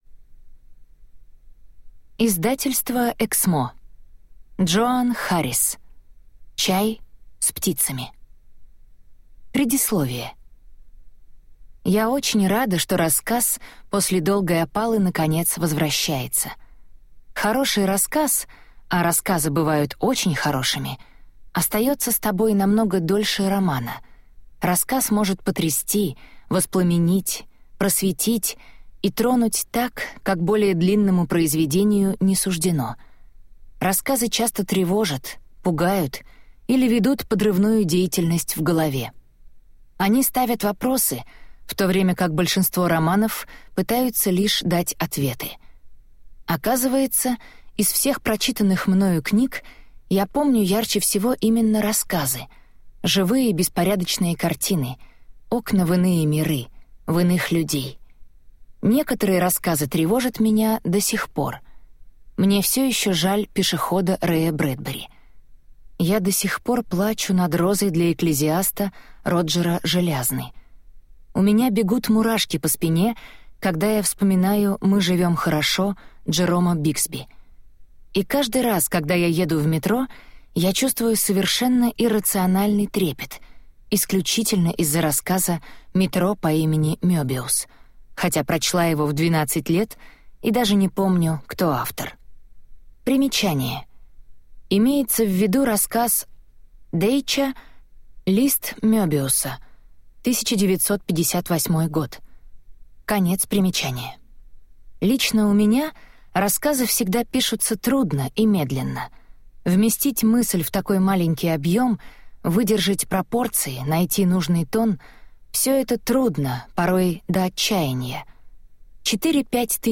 Аудиокнига Чай с птицами (сборник) | Библиотека аудиокниг